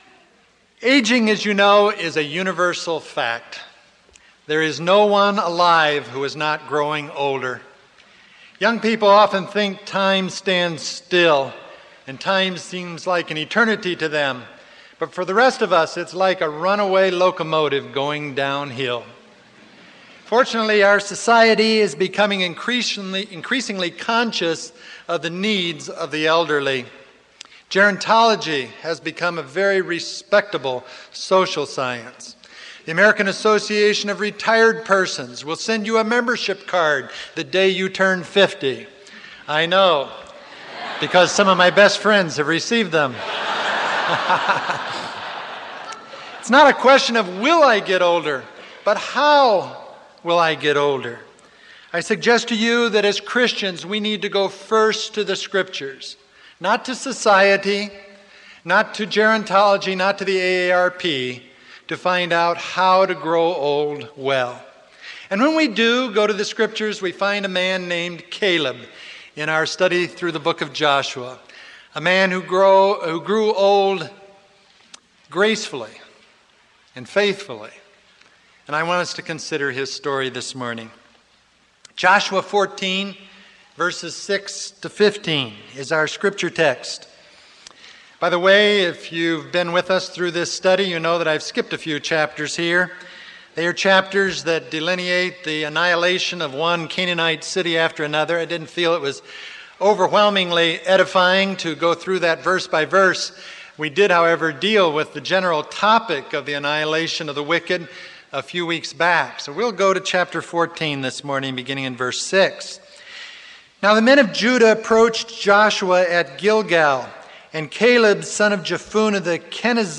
Joshua: Victory through Faith Caleb: Growing Old Gracefully TO HEAR THE ENTIRE SERMON CLICK THE LISTEN BUTTON ABOVE